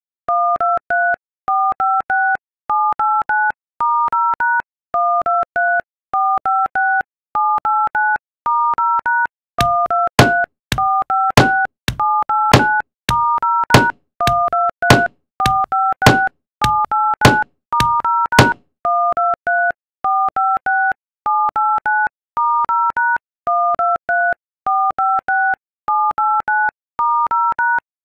Phone Keypad Tone Song Sound Effects Free Download